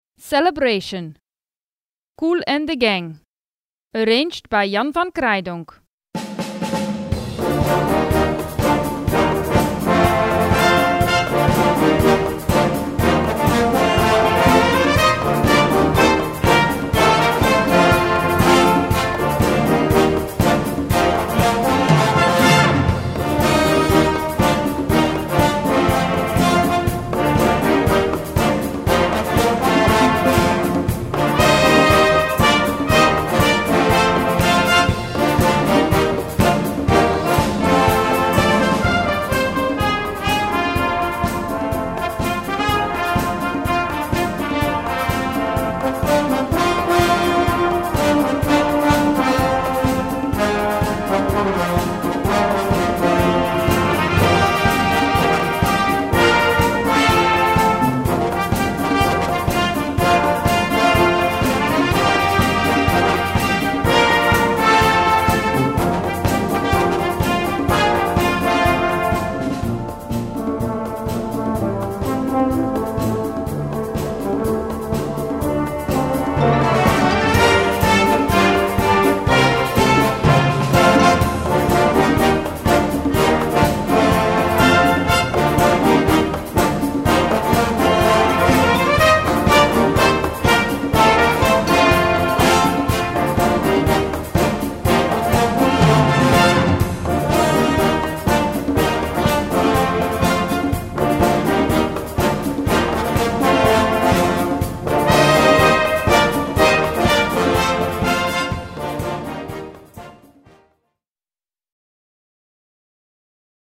Gattung: Popmusik
Besetzung: Blasorchester